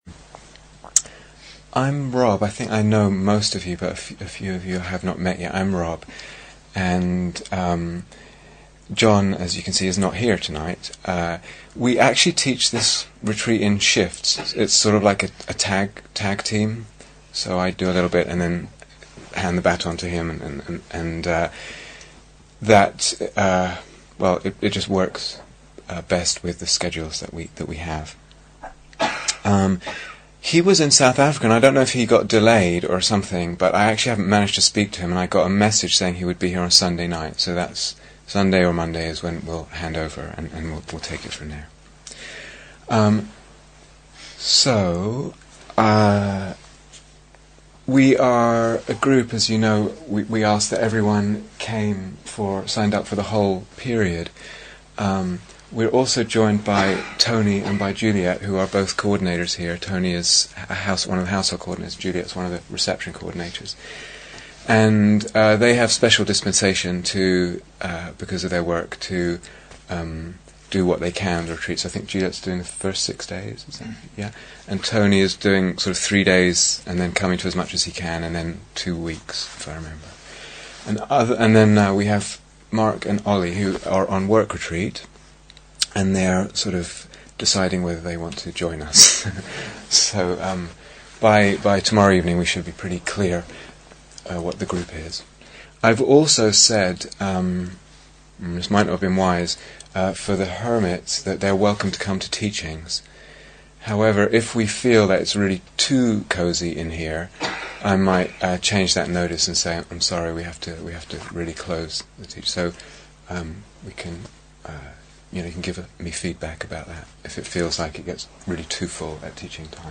Opening Talk - Orienting and Relating to the Emptiness Retreat
Here is the full retreat on Dharma Seed Please note that these talks are from a 4 week retreat for experienced meditators.